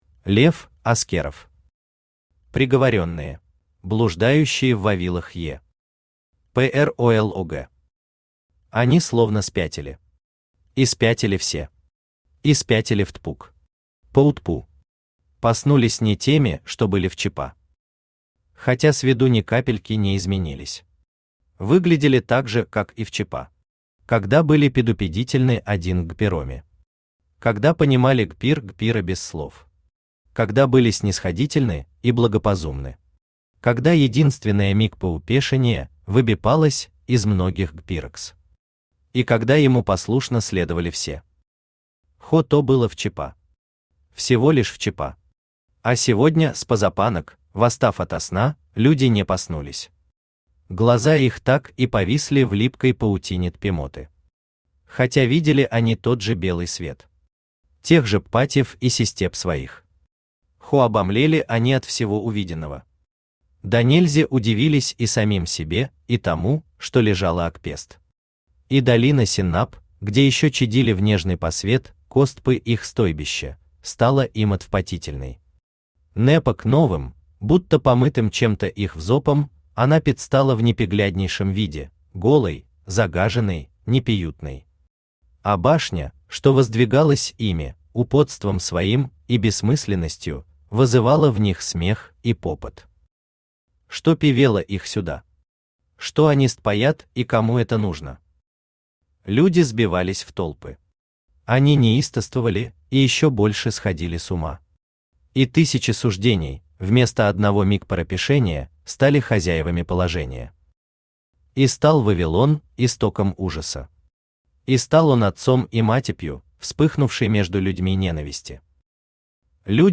Аудиокнига Приговоренные | Библиотека аудиокниг
Aудиокнига Приговоренные Автор Лев Аскеров Читает аудиокнигу Авточтец ЛитРес.